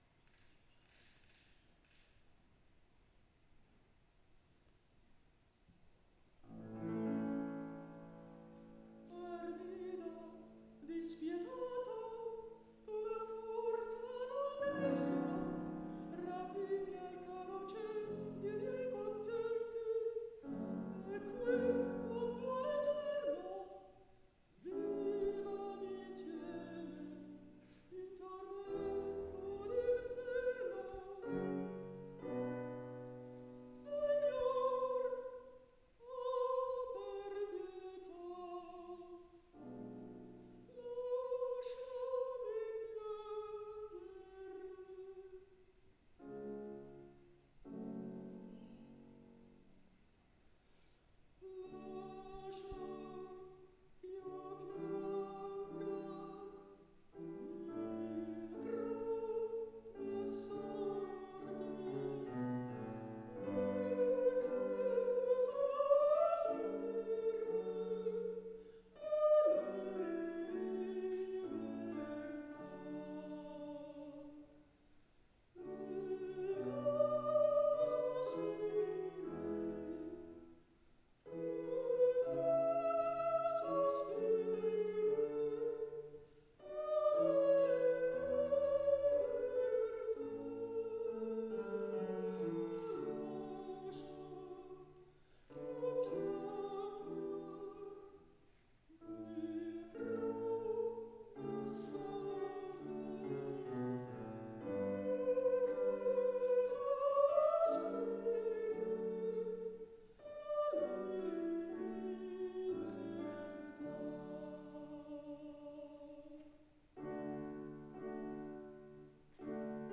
International Summer Academy of Music, Nice, France